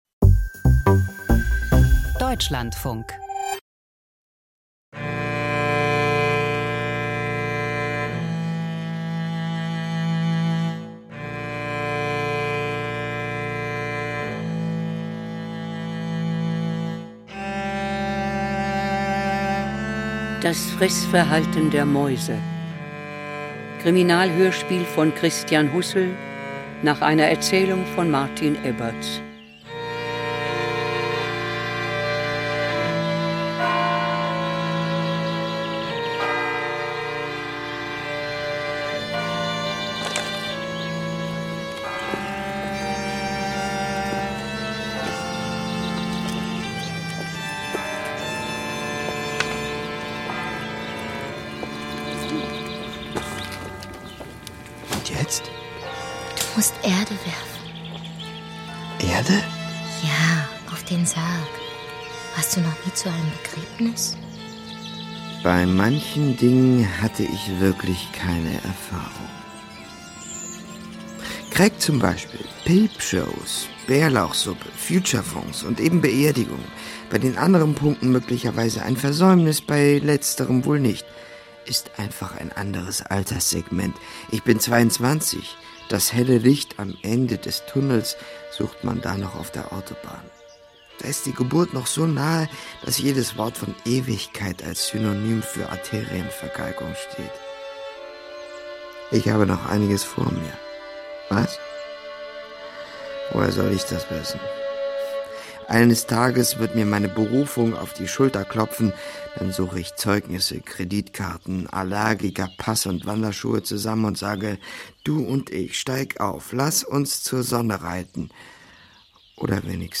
Krimi-Komödie über einen zweifelhaften Selbstmord